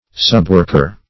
Subworker \Sub*work"er\, n. A subordinate worker or helper.